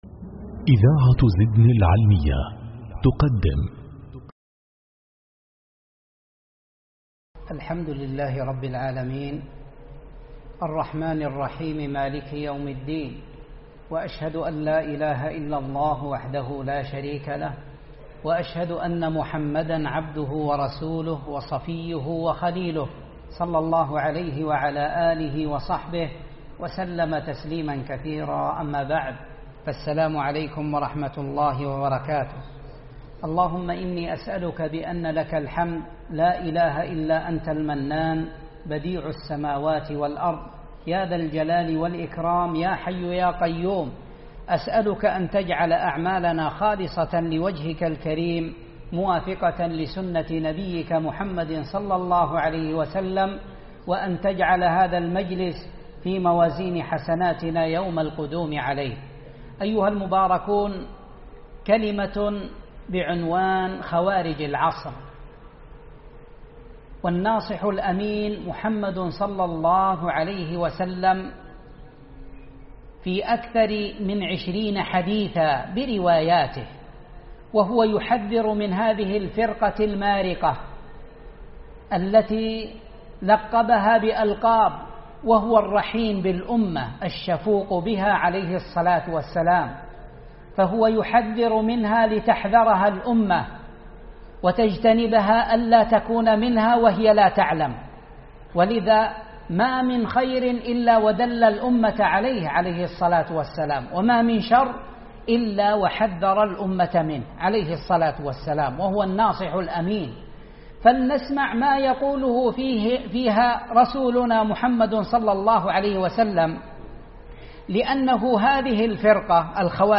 محاضرة
جامع الشيخ حافظ الحكمي رحمه الله بصامطة